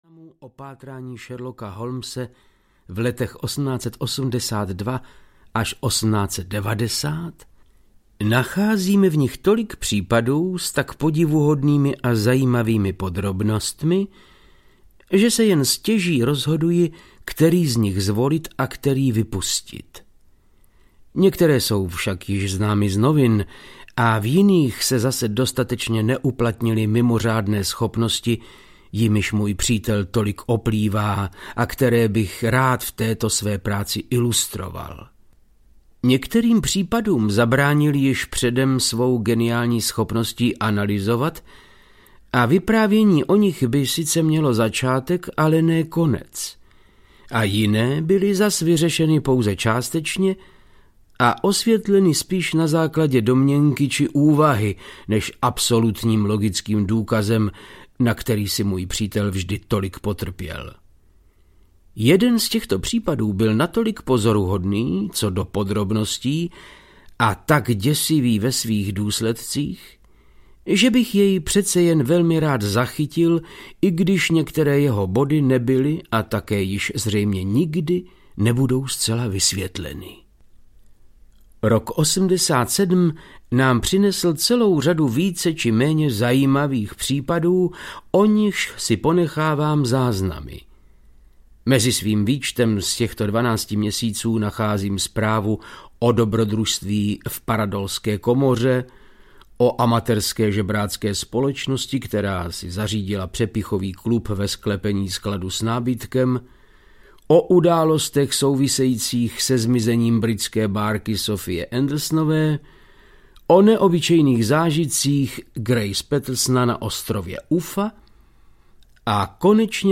Pět pomerančových jadérek audiokniha
Ukázka z knihy
• InterpretVáclav Knop